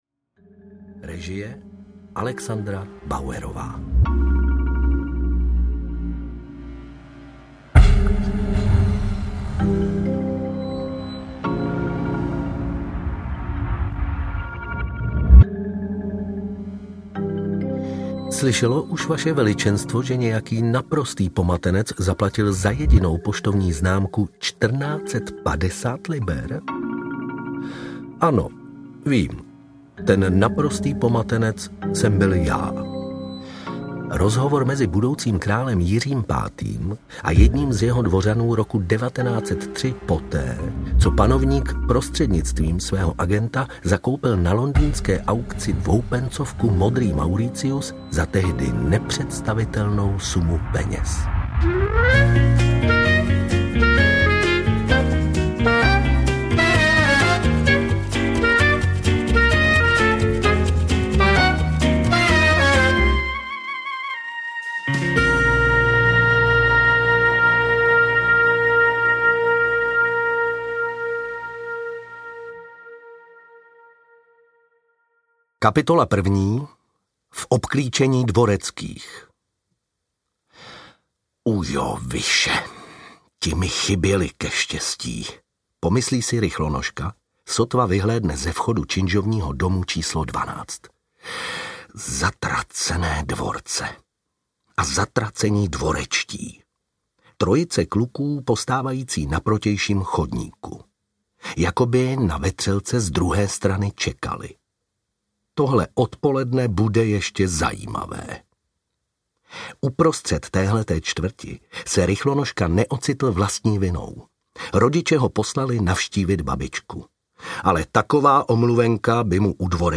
Život a smrt Maxmiliána Drápa audiokniha
Ukázka z knihy